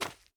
Jump_3.wav